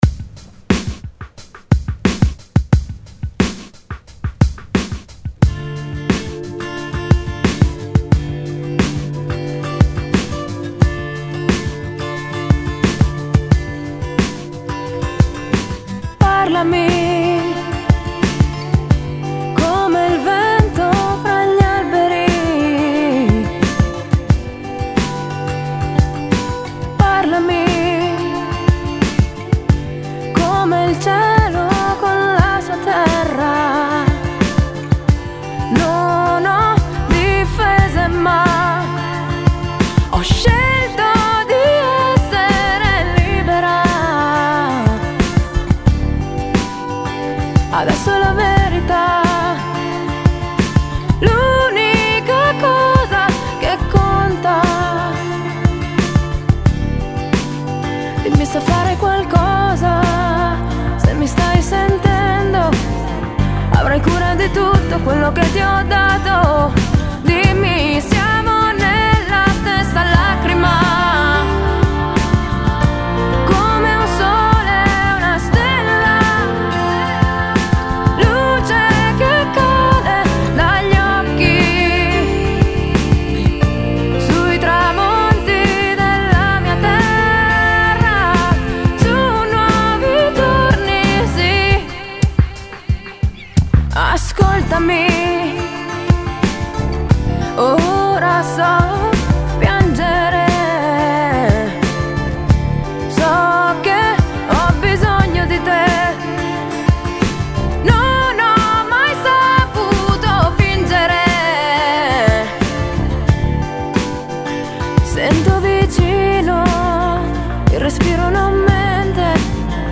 cover di vario genere riarrangiate in chiave acustica